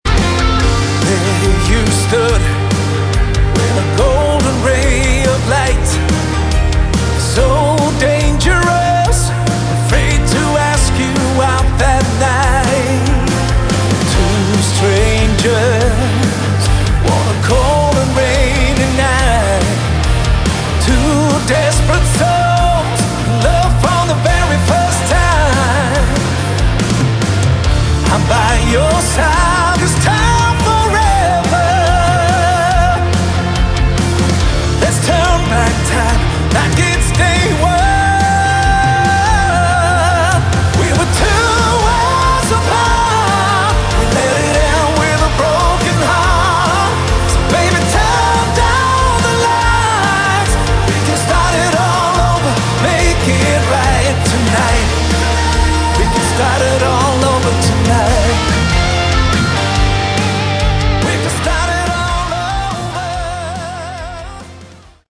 Category: Modern Hard Rock
lead vocals, backing vocals